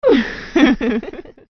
Folly No Reverb Sound Button - Free Download & Play